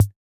RDM_Raw_MT40-Kick02.wav